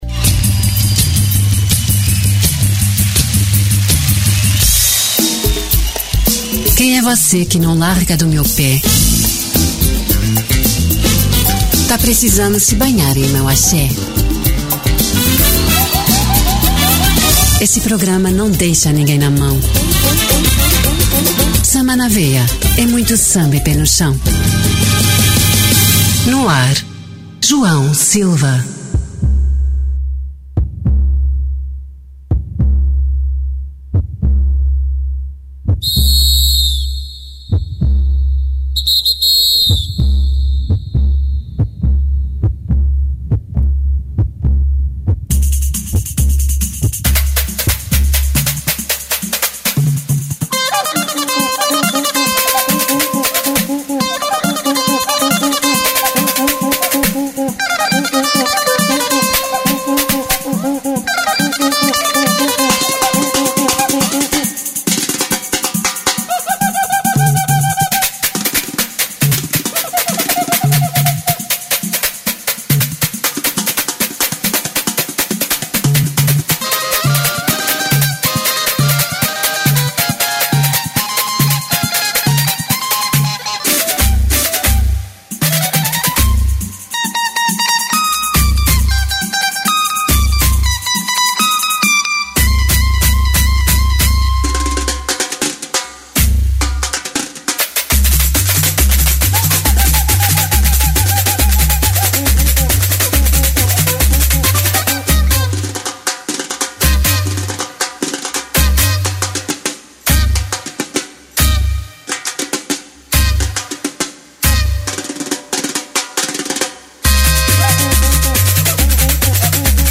Samba de Raíz